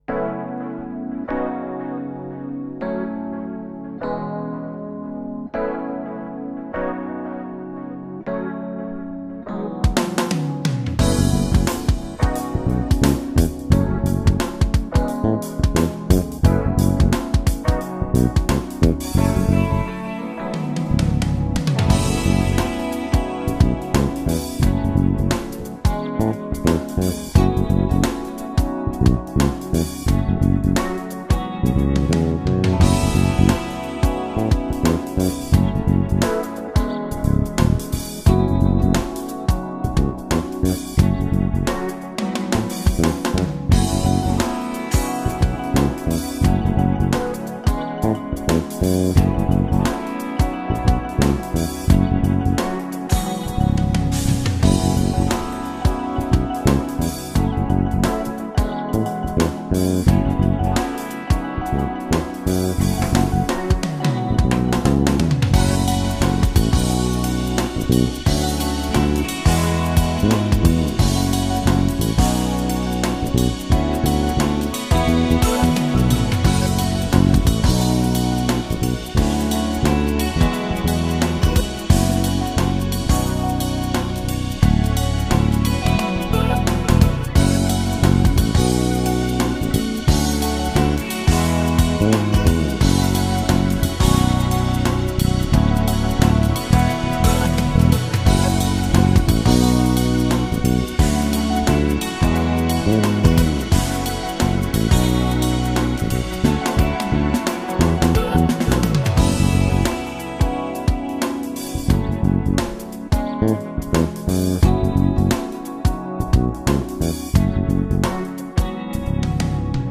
Jedná se 5ku Lakland Joe Osborne 5 - USA model, palisandrový hmatník, hráno na snímač u kobylky, protože tam je podle mne rozdíl nejvíce slyšet.
Hčko se mi líbí víc a basový "spodek" je za mě více artikulovaný.